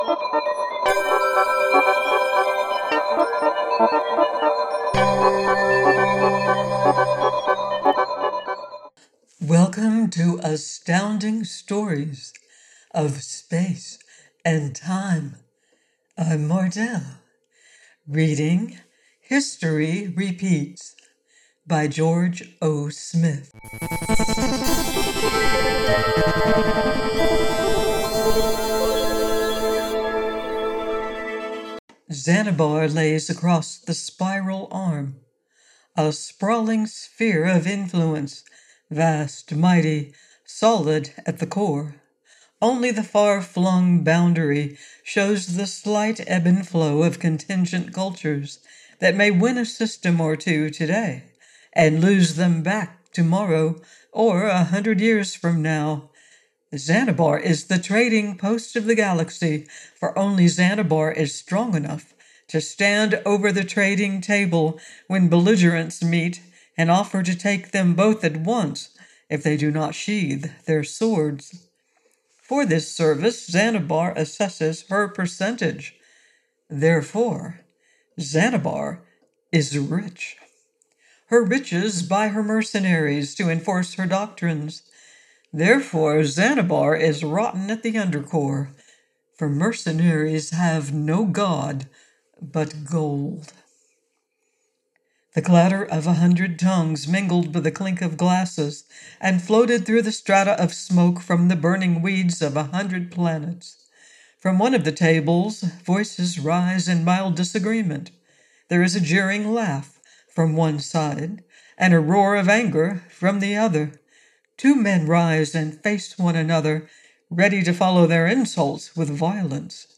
History Repeats by George O. Smith - AUDIOBOOK